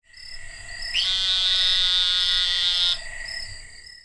Sinaloan Narrow-mouthed Toad - Gastrophryne mazatlanensis
Advertisement Calls
Sound  This is a very short recording of a single call from one Sinaloan Narrow-mouthed toad in the same group of toads.
gastrophrynesinglecall.mp3